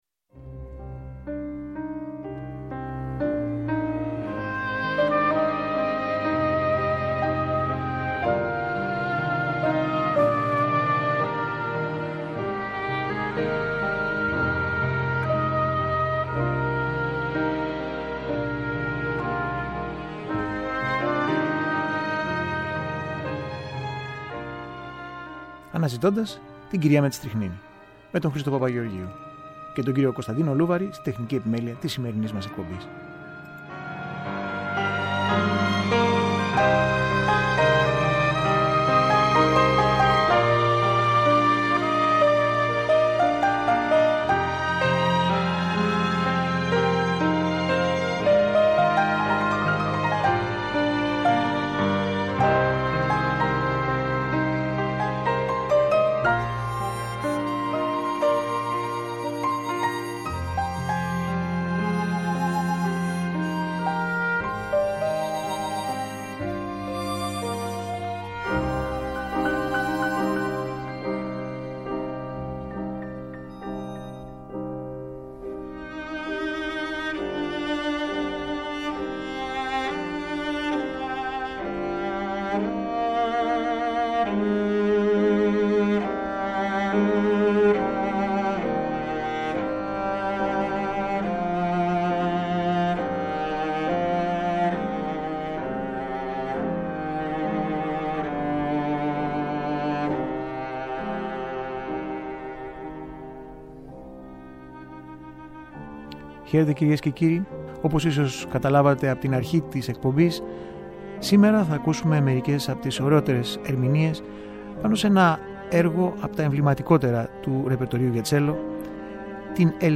Το κλαρινέτο στην κλασική και τζαζ εκδοχή του – Μέρος 3ο